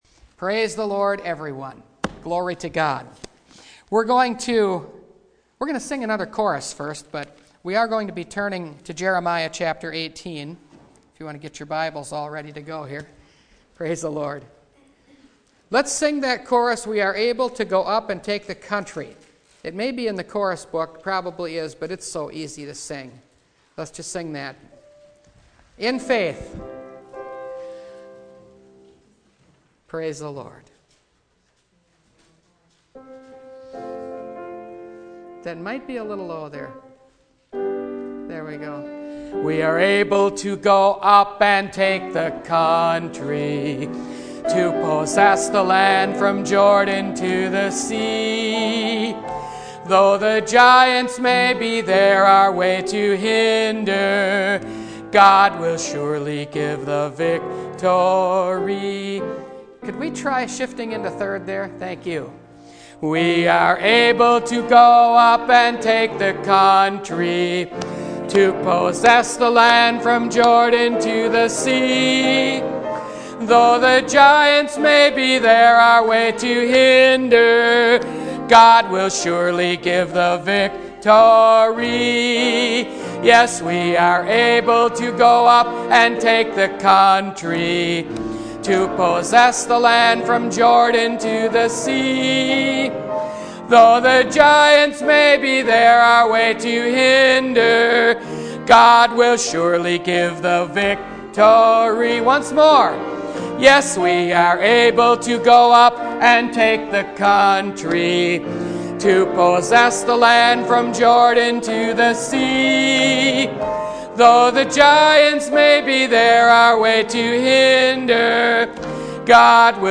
THE SERMONS